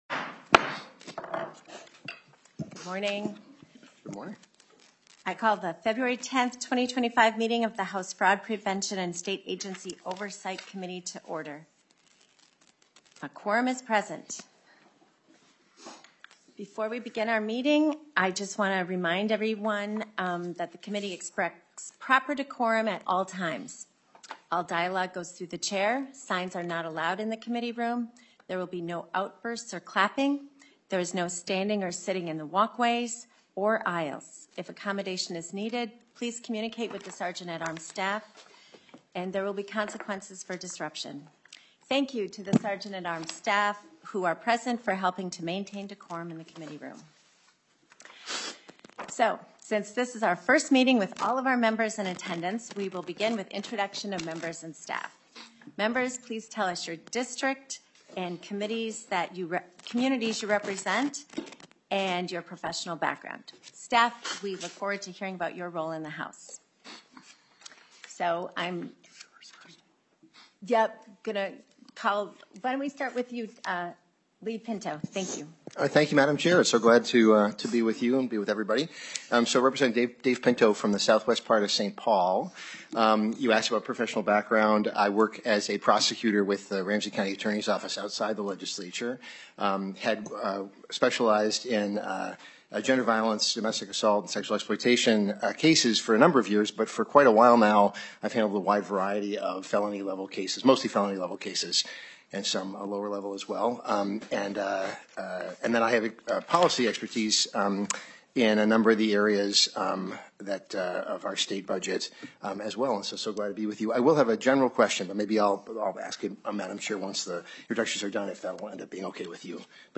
Fraud Prevention and State Agency Oversight Policy Committee